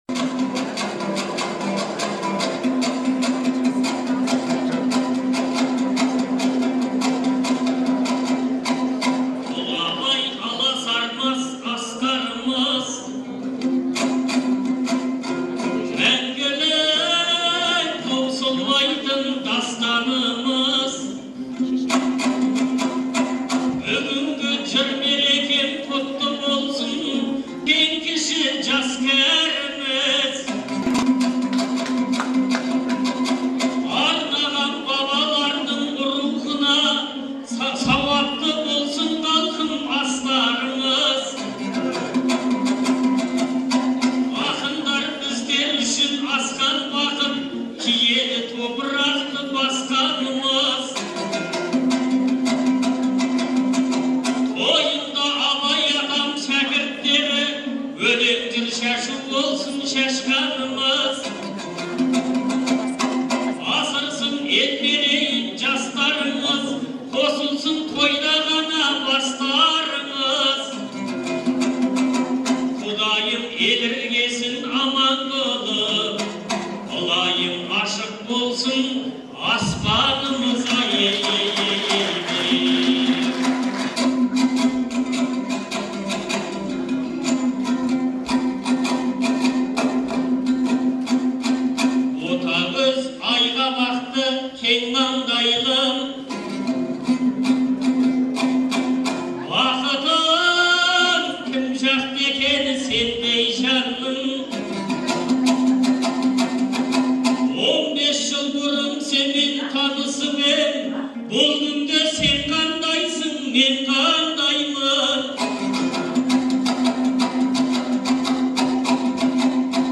Қыркүйектің 15-і күні Шығыс Қазақстан облысы Абай ауданының орталығы Қарауылда «Бабалар тойы – ел тойы» деген атпен Көкбай Жанатайұлы мен Ақылбайдың туғанына 150 жыл, Шәкір Әбеновтің туғанына 110 жыл толуына орай республикалық айтыс өтті.
айтысы